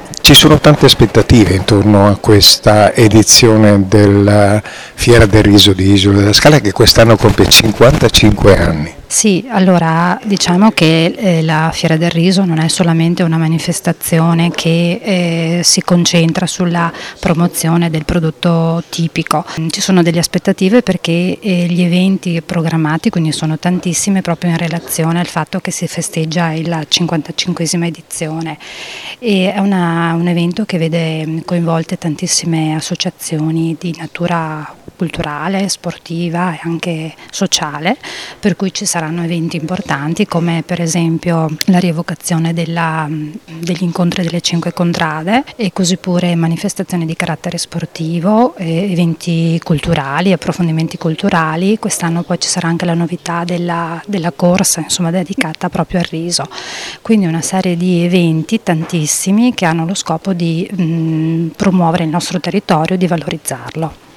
Le dichiarazioni raccolte dal nostro corrispondente
Matilde Perbellini, Assessore del Comune di Isola della Scala